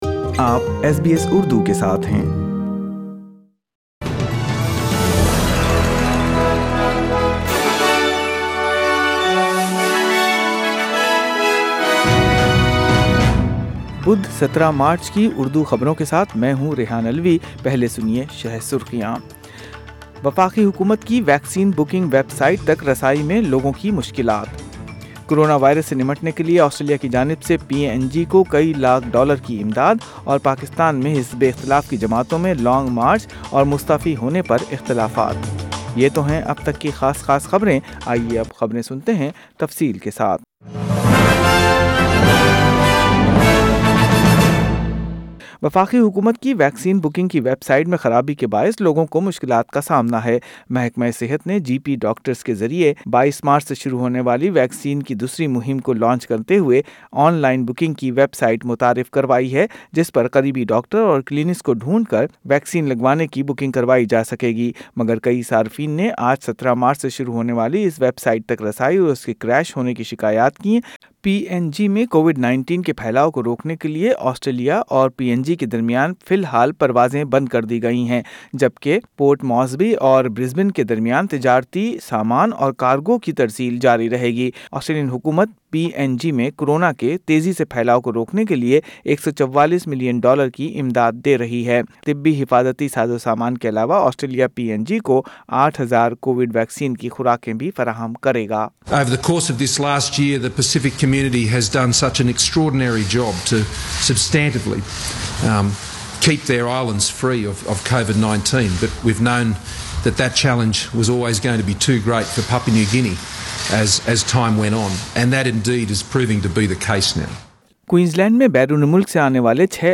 Urdu News WED 17 Mar 2021